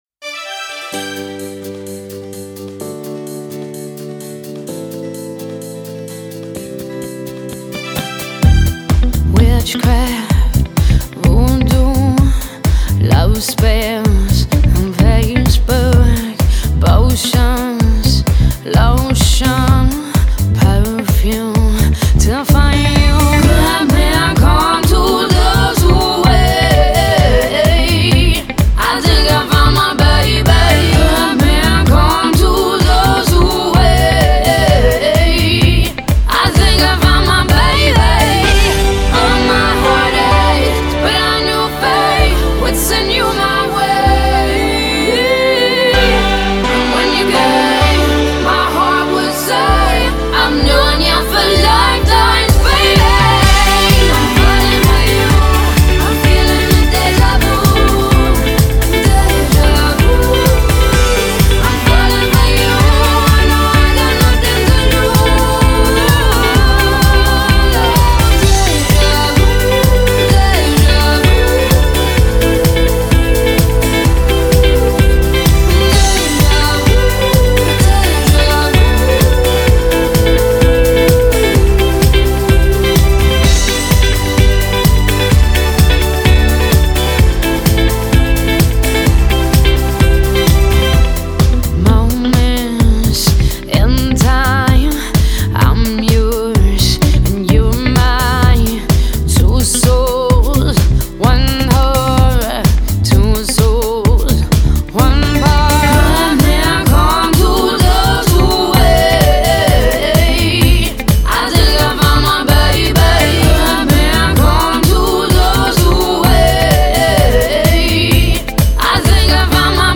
Жанр: Electronic